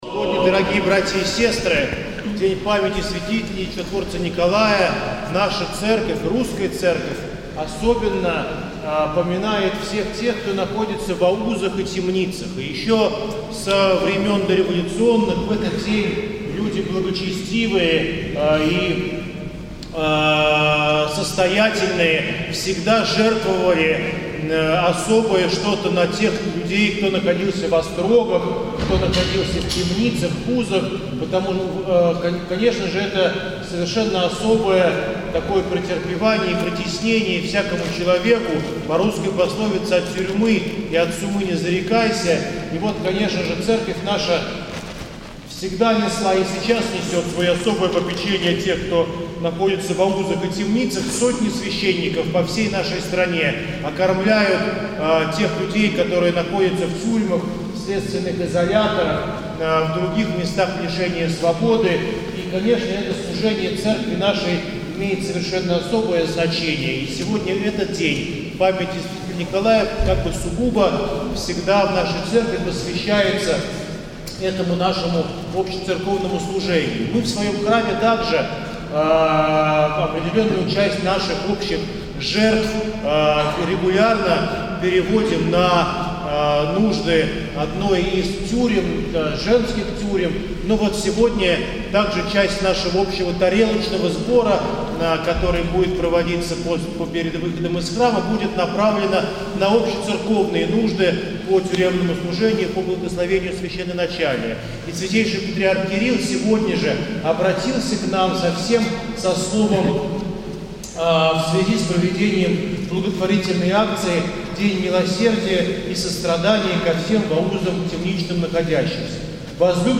по окончании Литургии